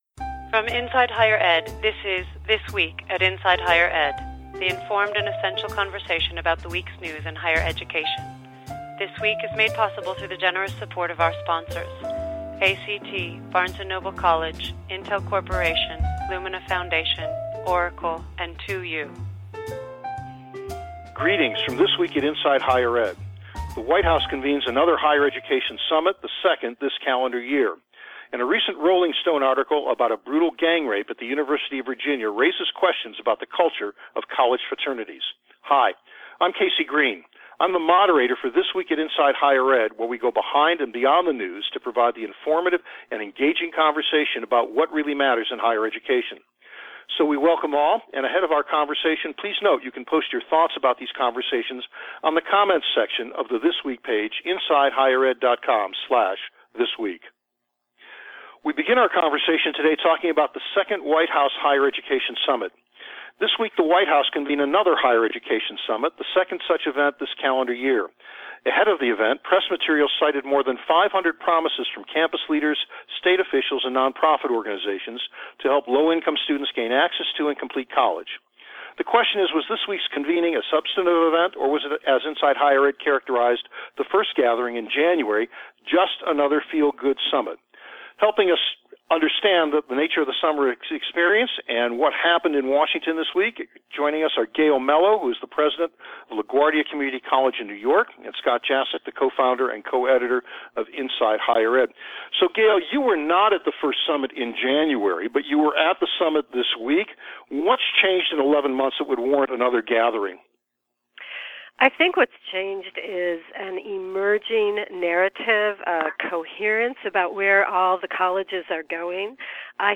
for a discussion about the history and future of the campus fraternity culture, in the wake of more tumult about alcohol, sexual assault and other issues.